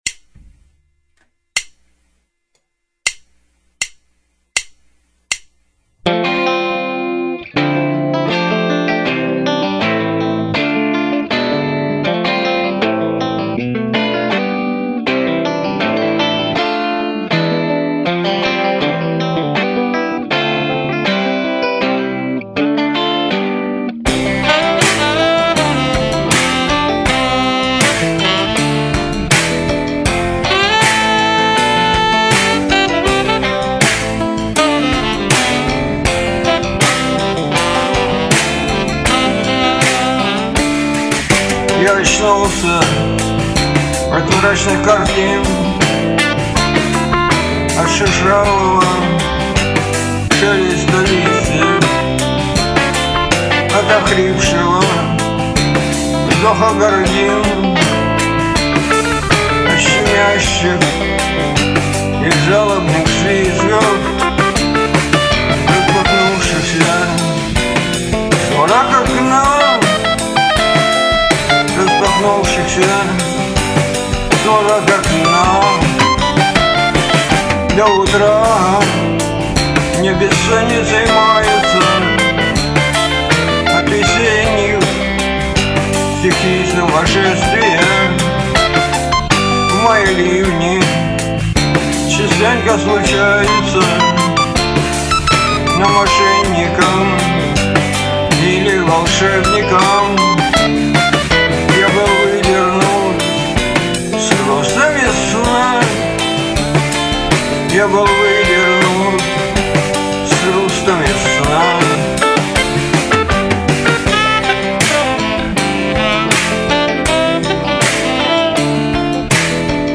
Фанк (337)